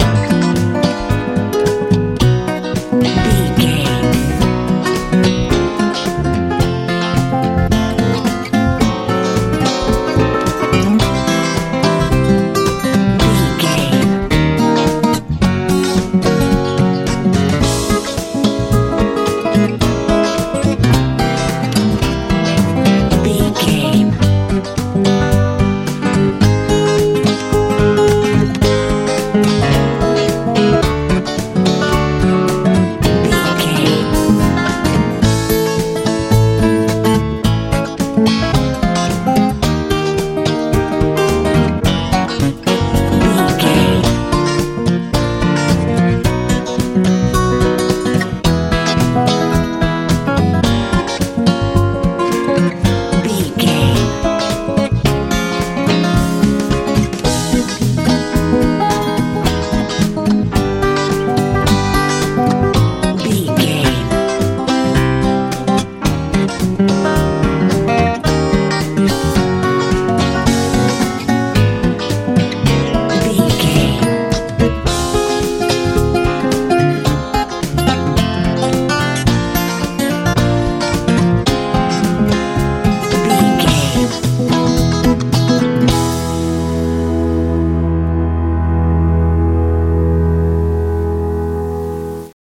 funk feel
Aeolian/Minor
A♭
happy
acoustic guitar
electric guitar
piano
drums
bass guitar
joyful